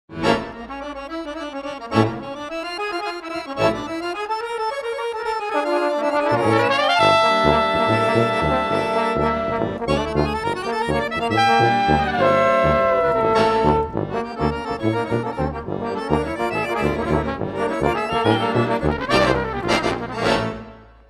Une rencontre insolite, accordéon et cuivres!
Accordéon
Trombone
Trompette
Tuba